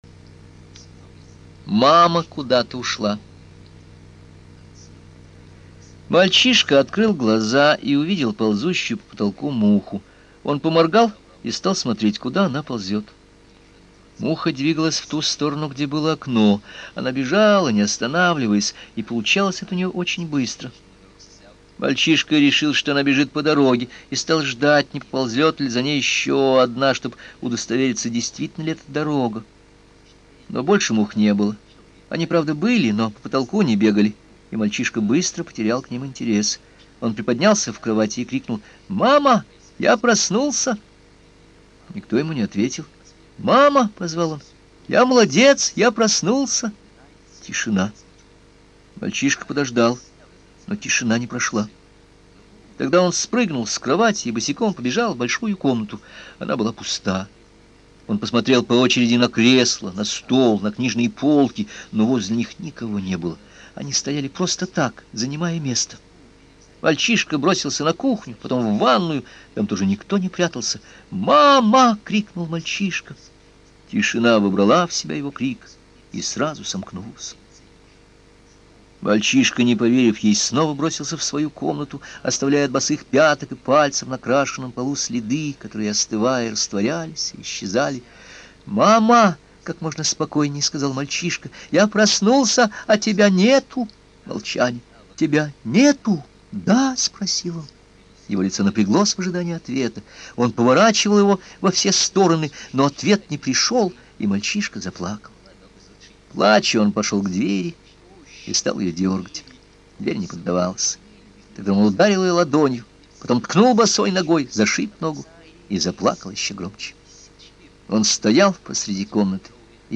Мама куда-то ушла - аудио рассказ Распутина В.Г. Рассказ про маленького мальчика, который впервые в своей жизни ощути, что такое одиночество.